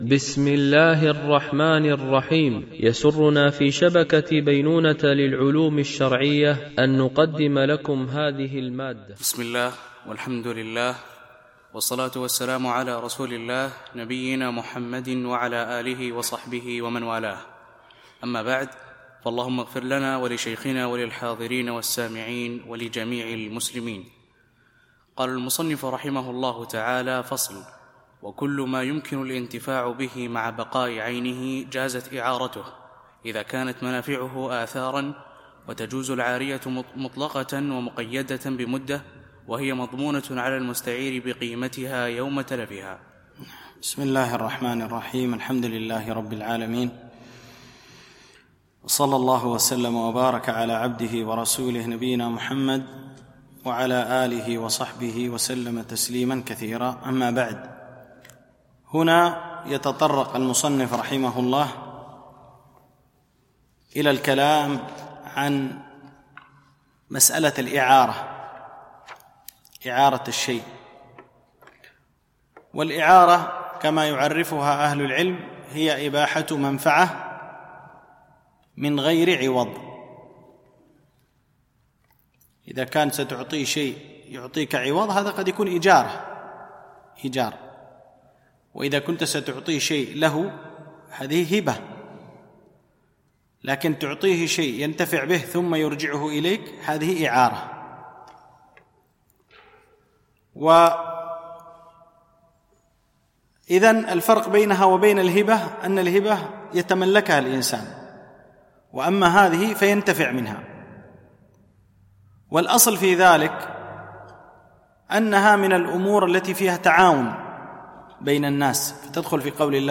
شرح متن أبي شجاع في الفقه الشافعي ـ الدرس 29